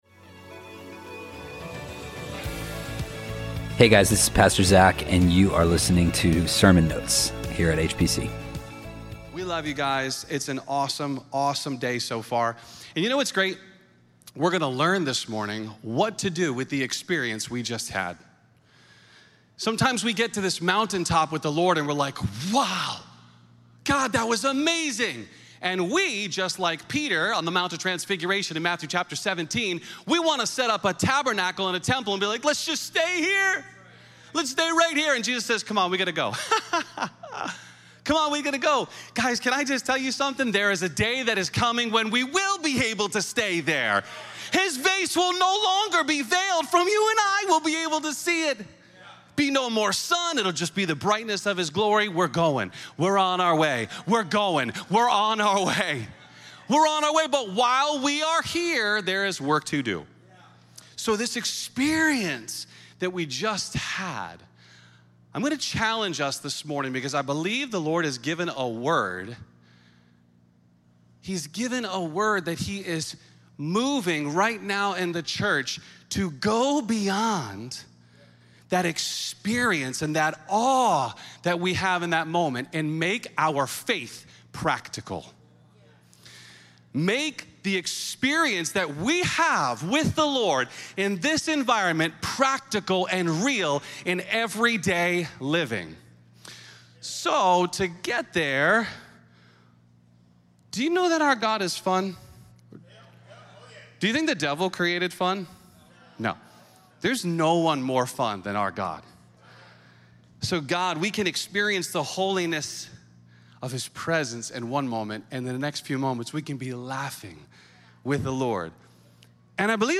HPC Sermon Notes Podcast - Practical Faith - Faith that Pleases God | Free Listening on Podbean App